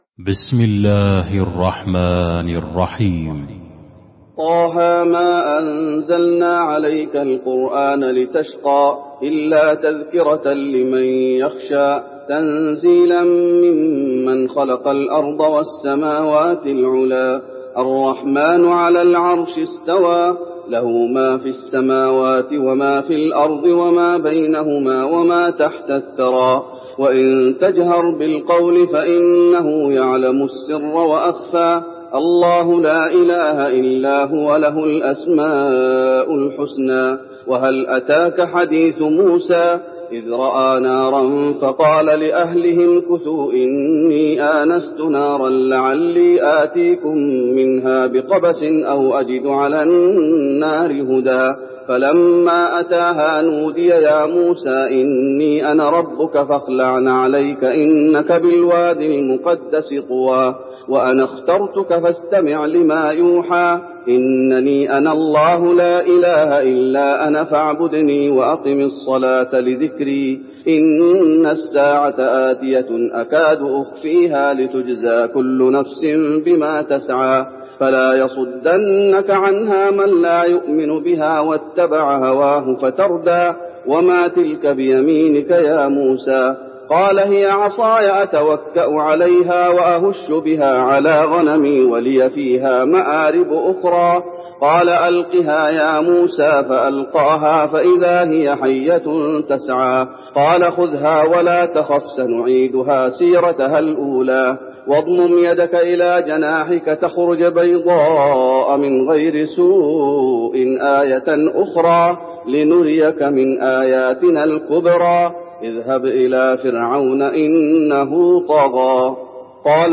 المكان: المسجد النبوي طه